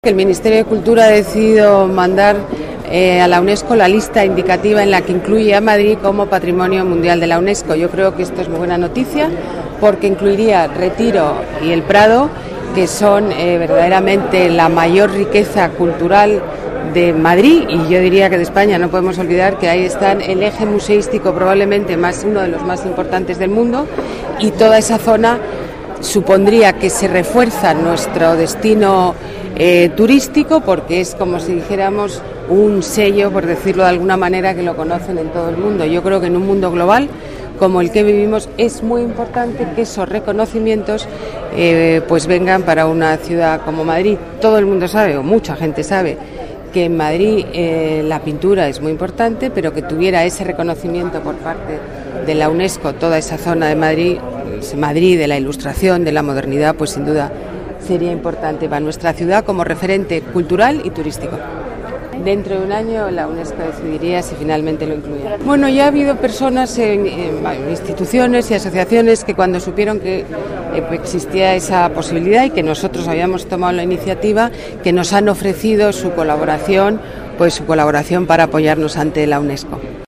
Nueva ventana:Declaraciones Ana Botella: El Sitio del Retiro y el Prado, lista Patrimonio Mundial de la Unesco